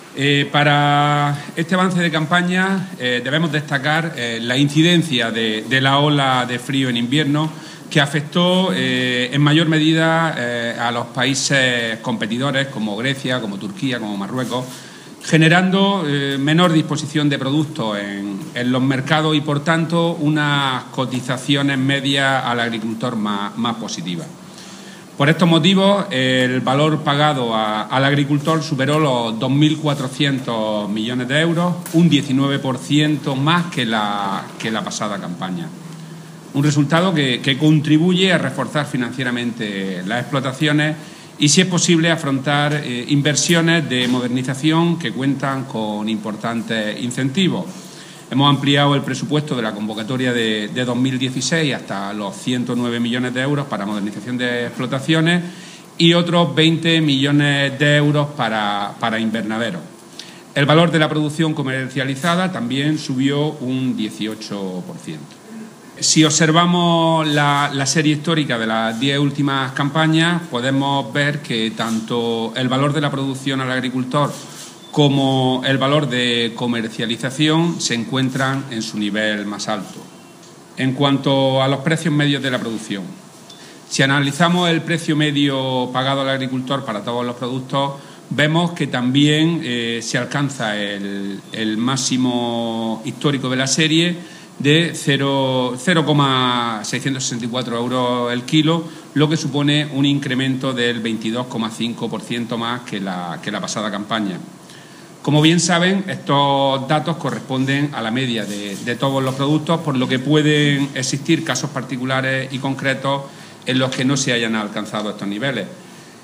Declaraciones de Rodrigo Sánchez sobre el avance de campaña hortofrutícola de Almería 2016-2017